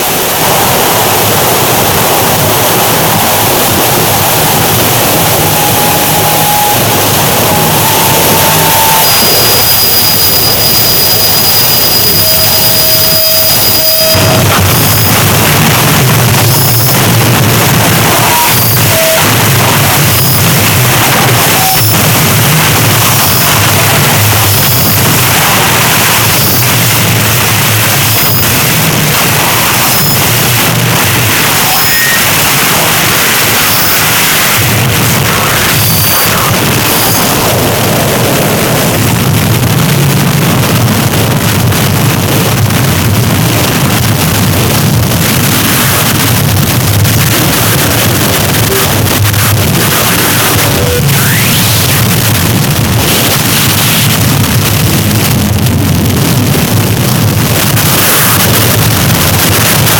a straight forward noise piece